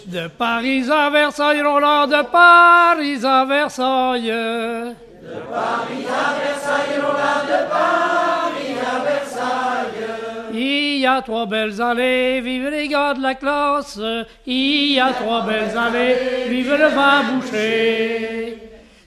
chanteur(s), chant, chanson, chansonnette
Pièce musicale inédite